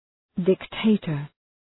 Shkrimi fonetik {dık’teıtər}